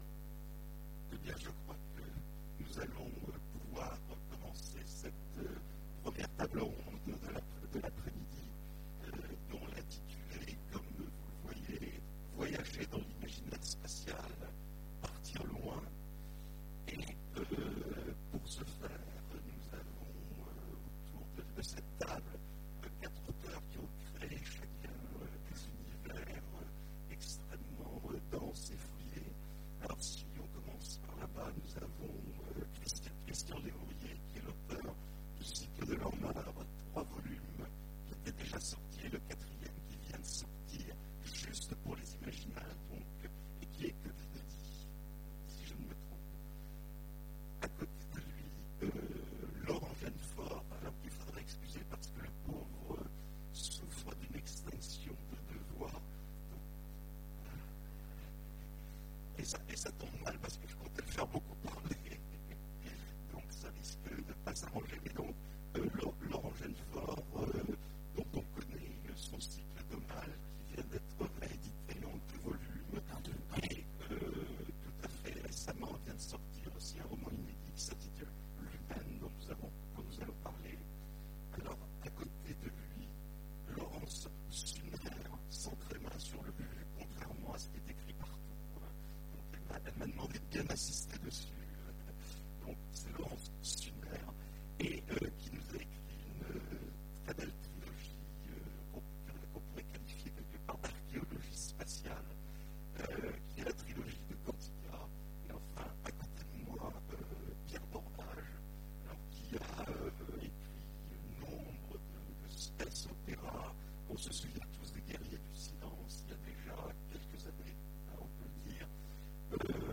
Imaginales 2015 : Conférence Voyager dans l'imaginaire spatial
Conférence